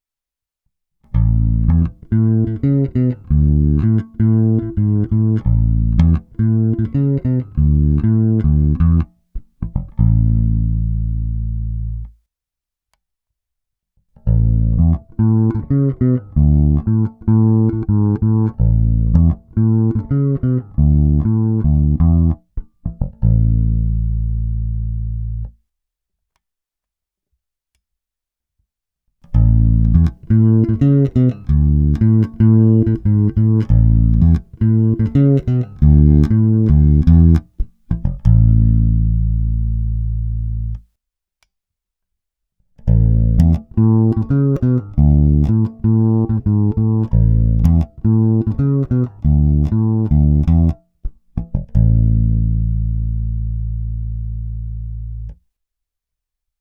Není-li uvedeno jinak, následující nahrávky byly provedeny rovnou do zvukové karty, korekce basů, středů i výšek byly přidány cca na 1/2, tónová clona vždy plně otevřená. Hráno vždy blízko krku.
První tři ukázky mají vždy čtyři části v pořadí: 1) singl, 2) humbucker, 3) singl + piezo, 4) humbucker + piezo, přičemž mix pieza a magnetických snímačů byl cca 50/50.
Oba snímače